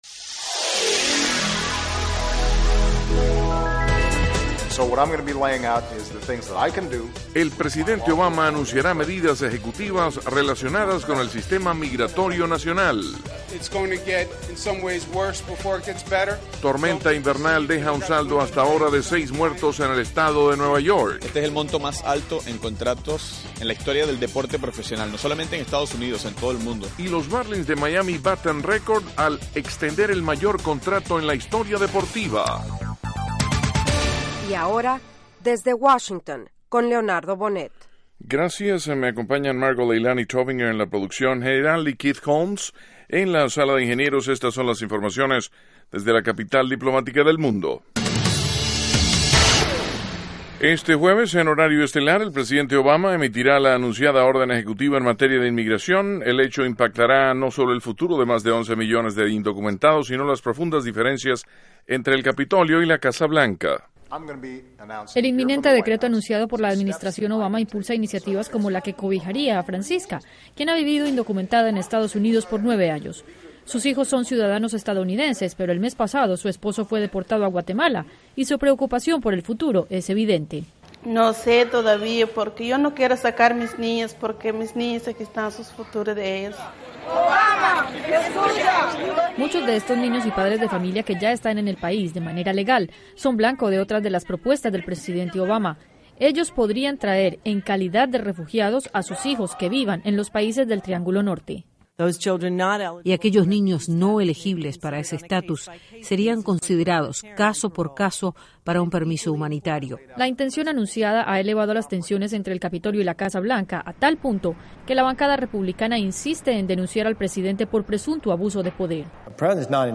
Diez minutos de noticias sobre los acontecimientos de Estados Unidos y el mundo.